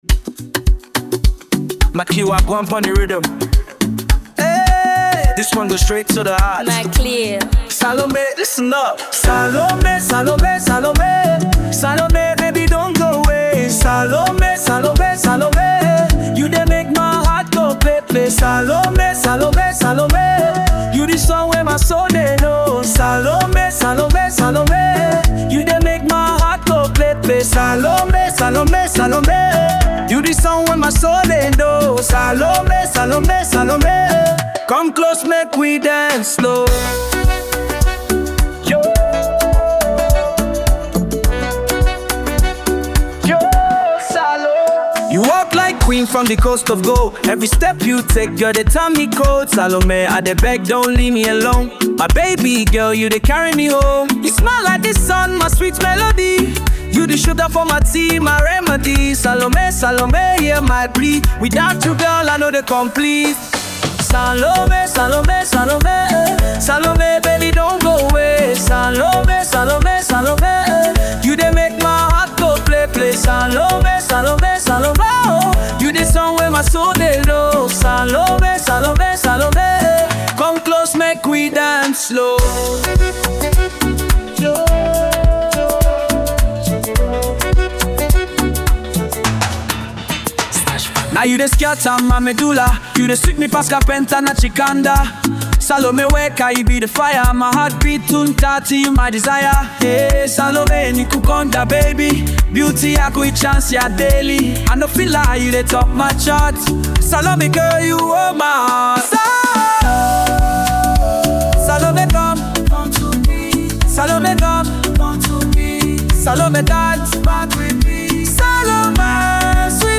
Genre: Afrobeats
a remarkable dancehall artist from Zambia.
is a great example of love music